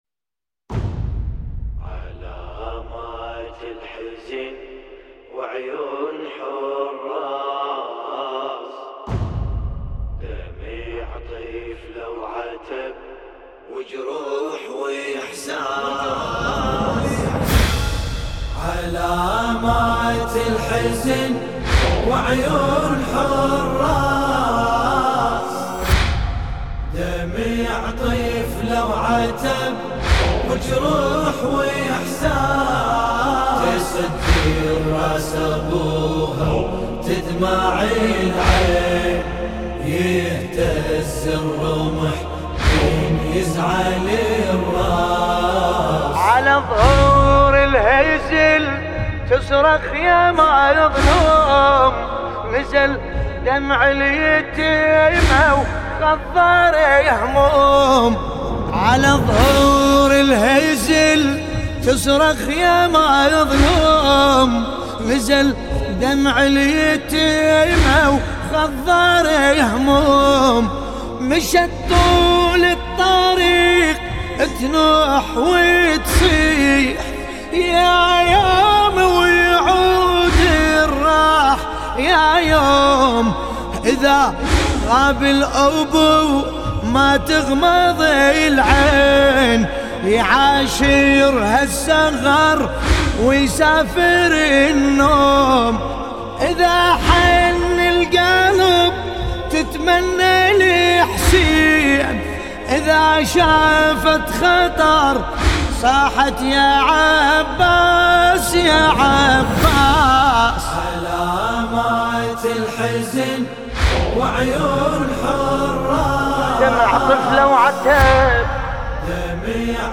سینه زنی